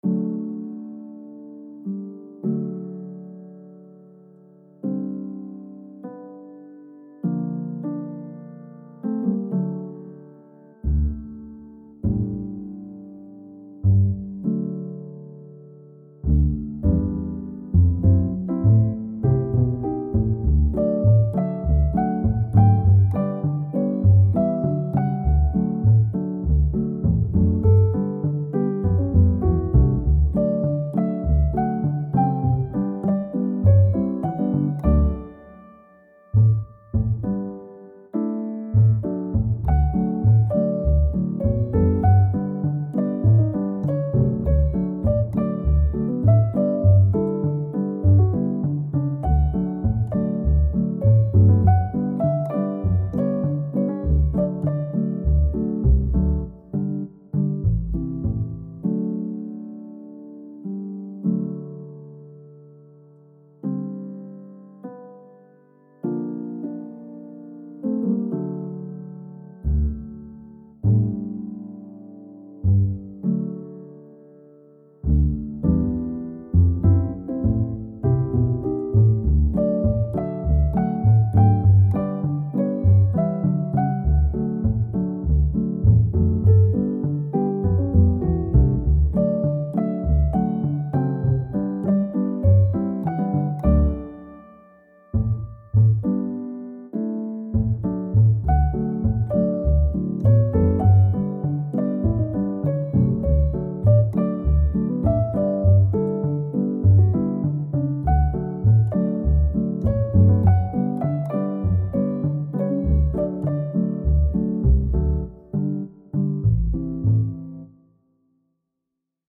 A happy peaceful melody for a small town :)